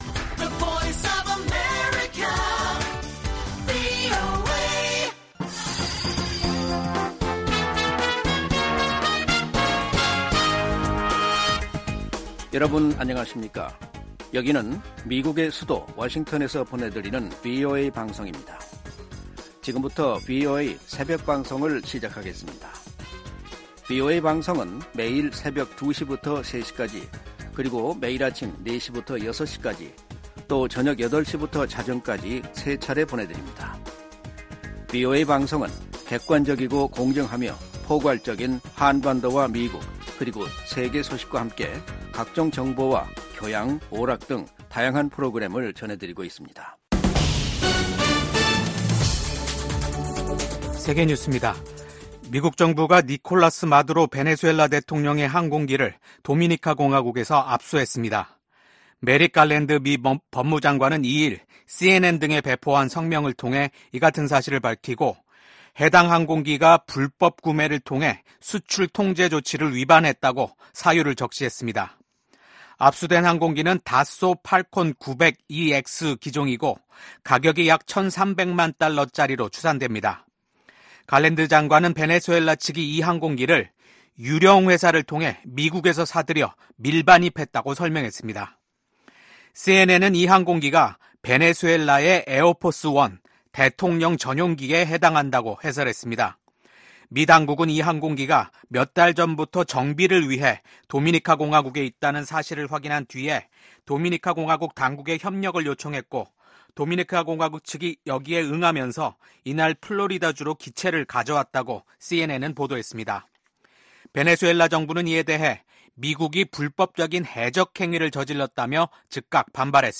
VOA 한국어 '출발 뉴스 쇼', 2024년 9월 4일 방송입니다. 신종코로나바이러스 사태 이후 최대 규모의 백신 접종 캠페인이 북한 전역에서 시작됐습니다. 유엔 사무총장이 북한의 열악한 인권 상황을 거듭 우려하면서 인권 유린 가해자들을 처벌하라고 촉구했습니다. 중국 정부가 중국 여자 프로농구에 진출한 북한 선수의 갑작스러운 귀국 보도와 관련해 이례적으로 ‘대북제재’를 언급했습니다.